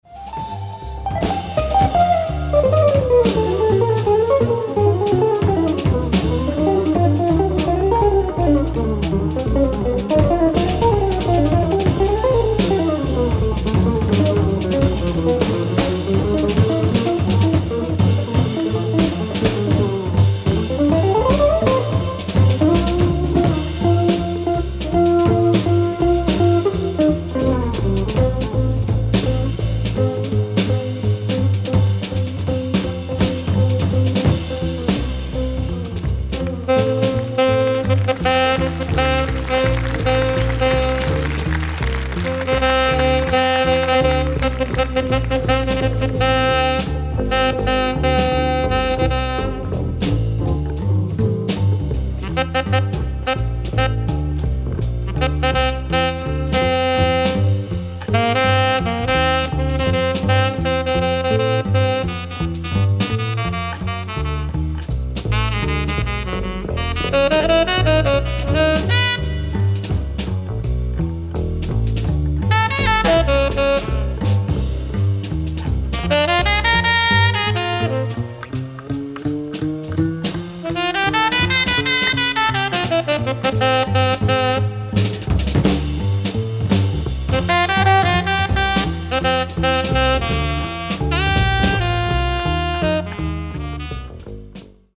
ライブ・アット・ハンブルグ市立公園、ハンブルグ、ドイツ 08/29/1981
※試聴用に実際より音質を落としています。